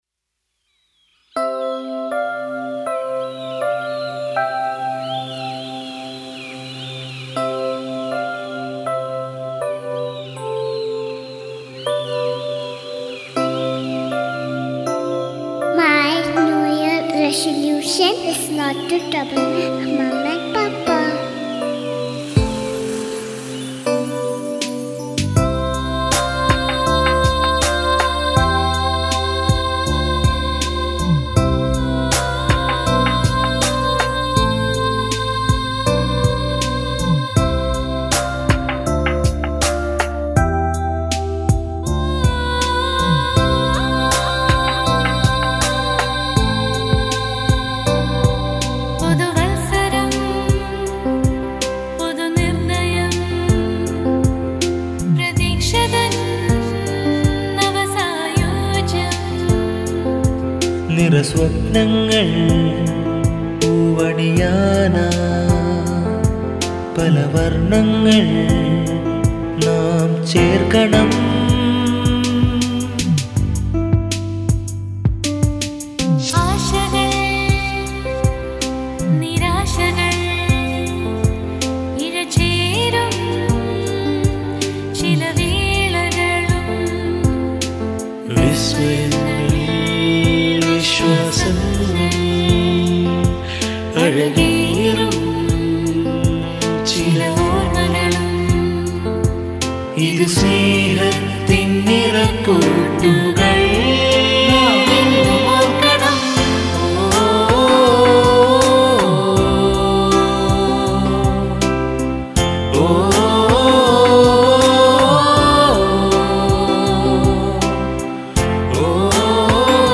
നാദത്തിന്റെ നാലാമത്തെ ഗാനോപഹാരം ഒരു പുതു വർഷഗാനമായി സമർപ്പിക്കുന്നു…